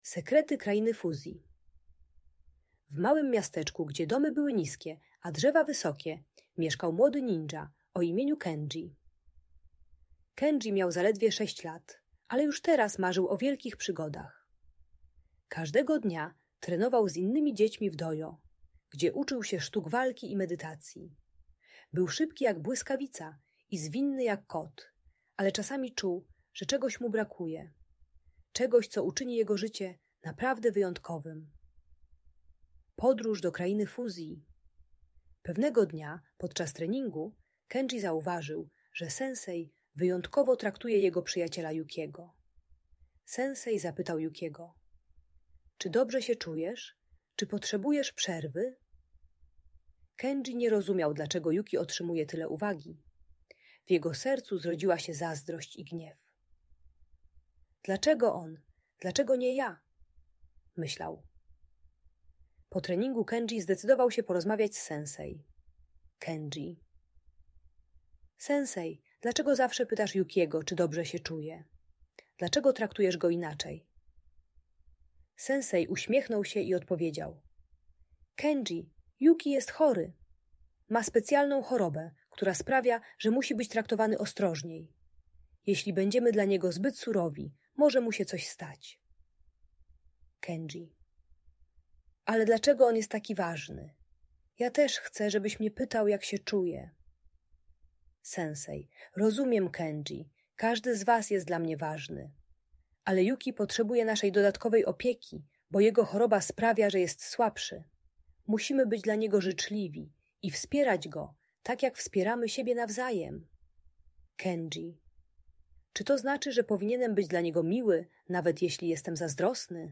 Sekrety Krainy Fuzji - Audiobajka